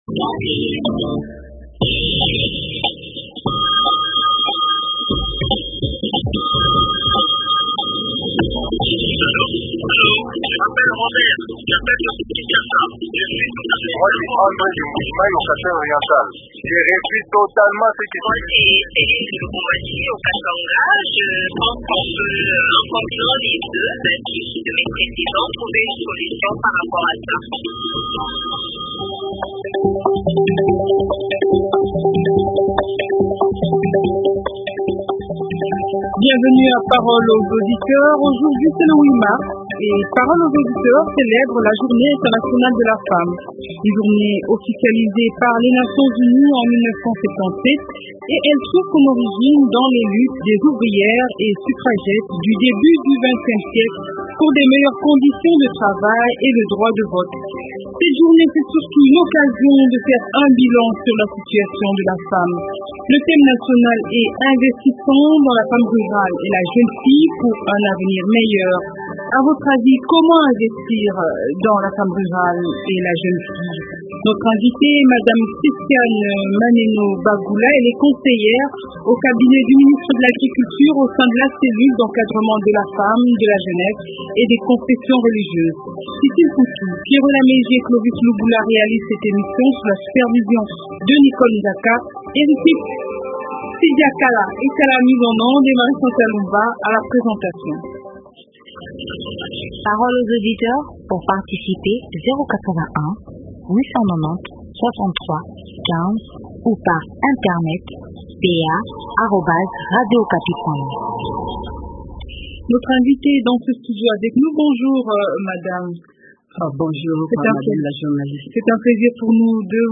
Aujourd’hui c’est le 8 mars, parole aux auditeurs célèbre la journée internationale de la femme.